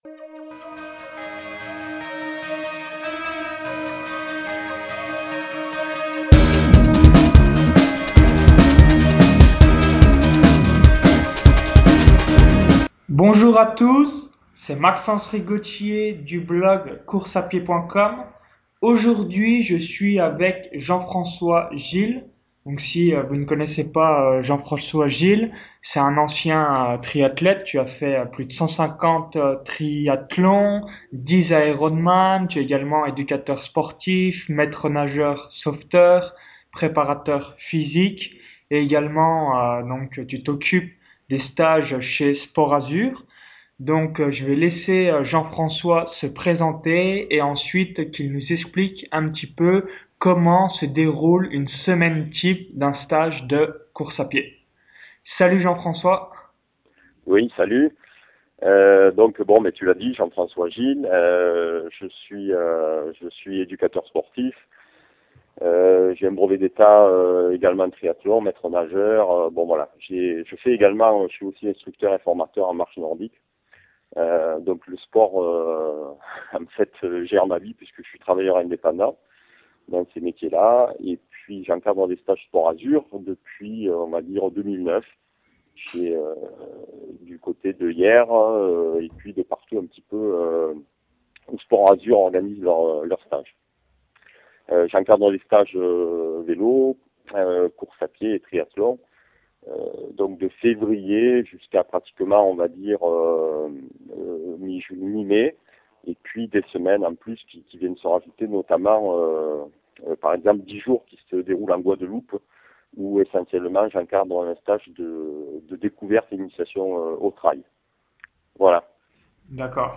[Interview audio]
L’interview au format audio: